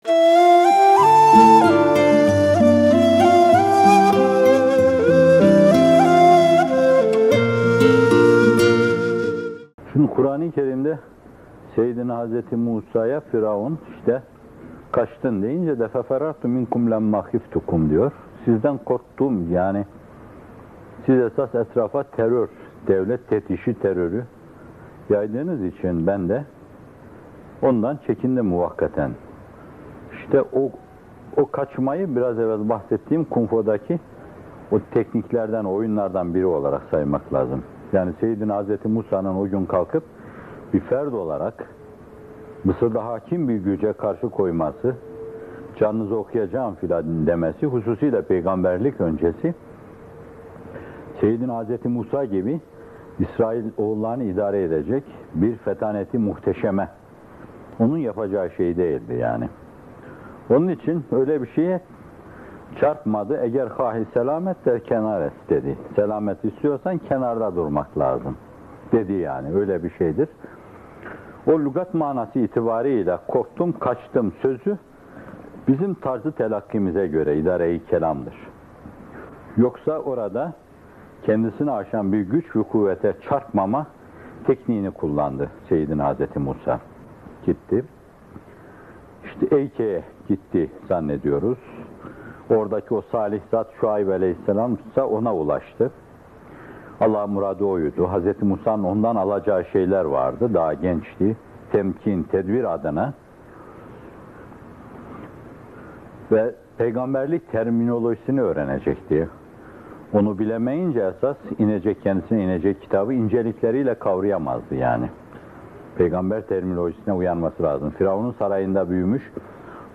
Kur’an’ın Altın İkliminde – Şuarâ Suresi 21. Ayet - Fethullah Gülen Hocaefendi'nin Sohbetleri